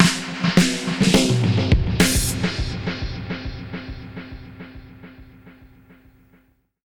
Index of /musicradar/dub-drums-samples/105bpm
Db_DrumsA_KitEcho_105_04.wav